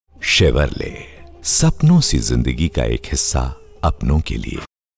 My voice ranges from middle aged to senior.
Deep, warm, soft, soothing, smooth, voice.
Sprechprobe: Sonstiges (Muttersprache):